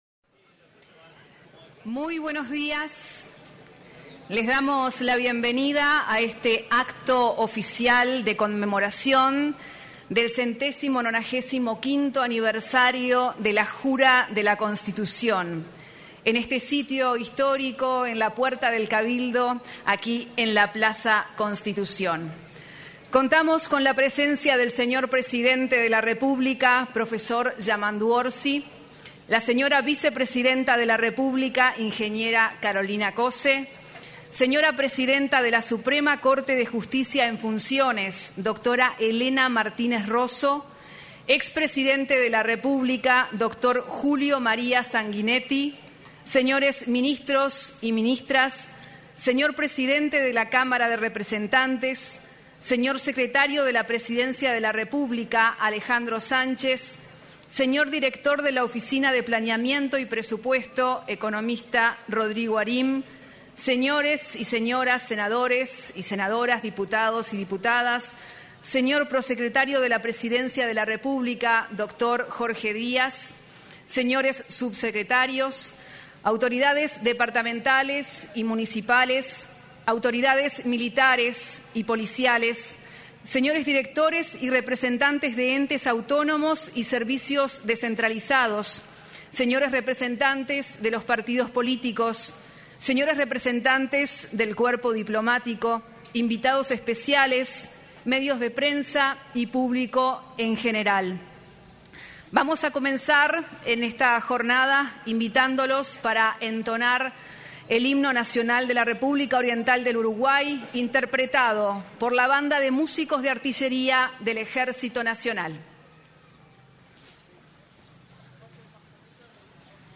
En la oportunidad, se expresó el ministro de Relaciones Exteriores, Mario Lubetkin. Una vez culminado el acto, se realizó un desfile cívico-militar.